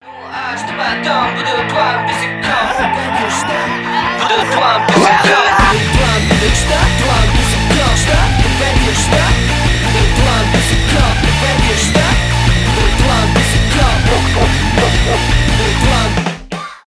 альтернатива rock , synth rock